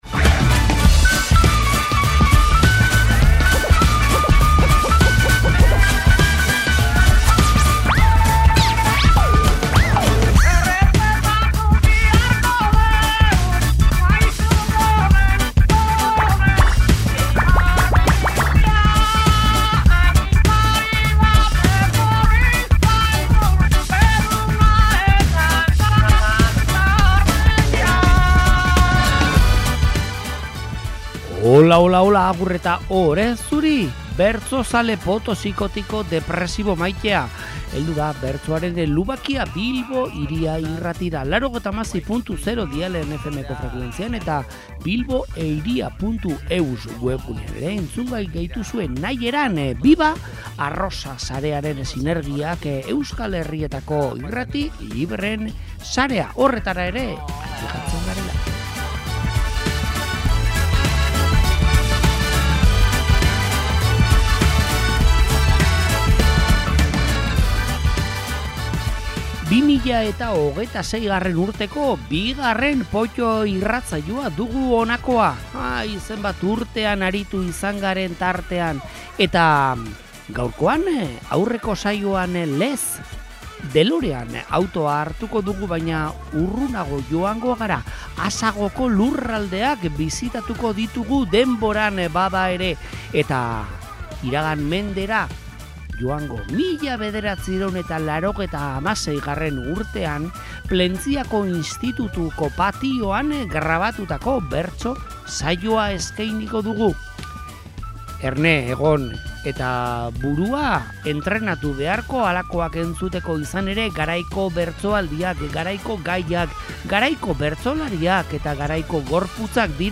Plentzia Telebista hedabidearen artxiboan aurkitutako harribitxiak, oparitarako paper ederrean bilduta: 1996. urtea dugu jomuga, Plentziako institutuan antolatutako bertso-saioa.